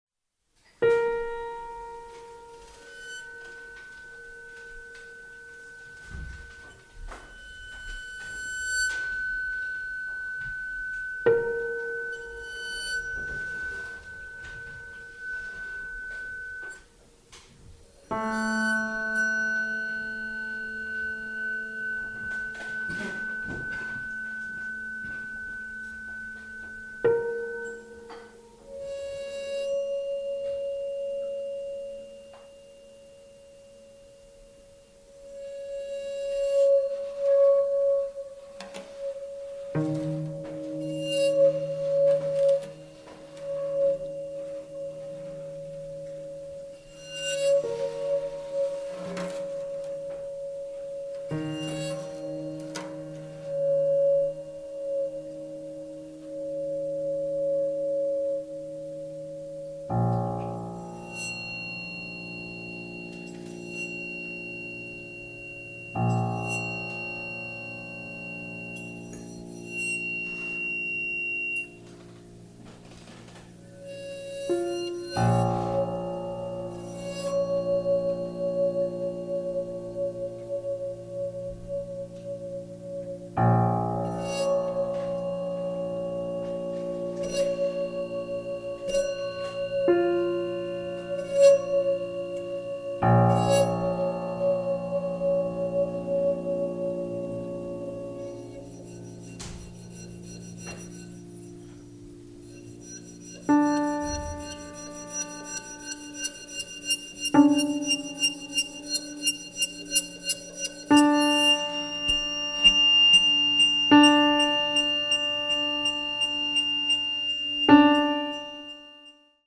French pianist